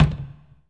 HOLZ       3.wav